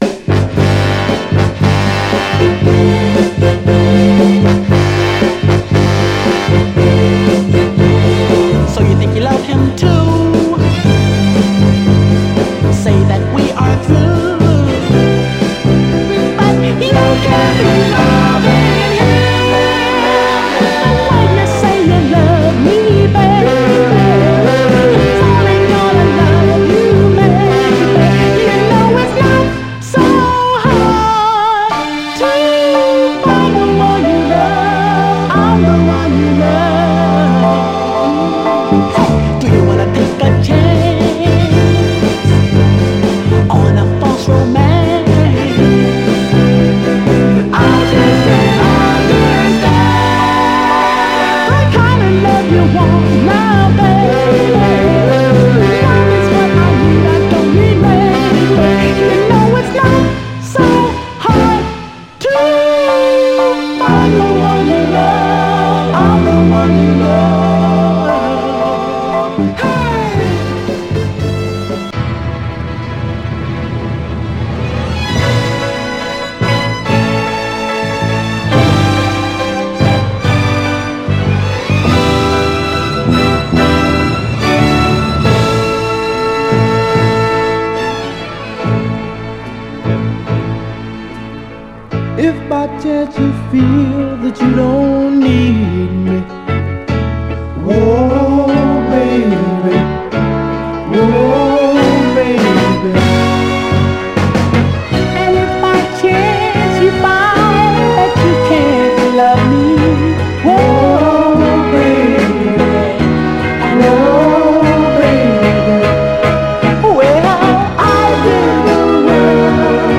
盤はエッジ中心にごく細かいスレ、細いヘアーラインキズありますが、グロスが残っておりプレイ良好です。
※試聴音源は実際にお送りする商品から録音したものです※